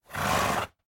Minecraft Version Minecraft Version snapshot Latest Release | Latest Snapshot snapshot / assets / minecraft / sounds / mob / horse / idle1.ogg Compare With Compare With Latest Release | Latest Snapshot